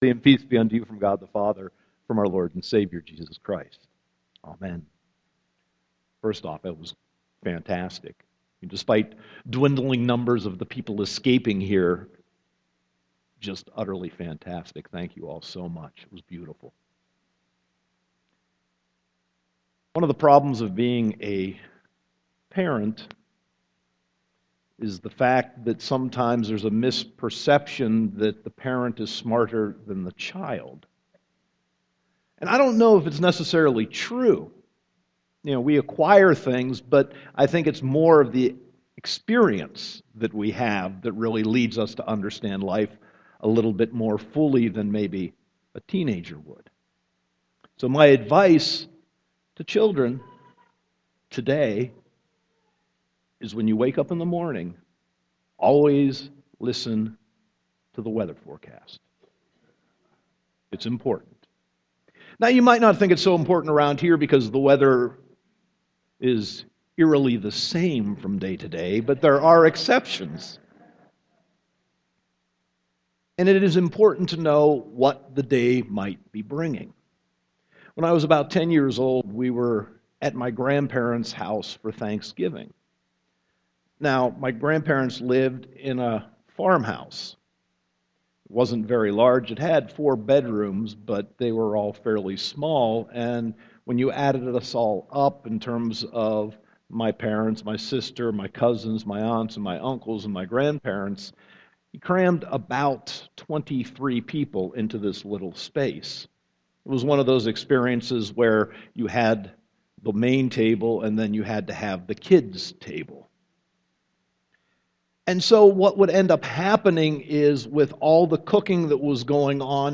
Sermon 5.18.2014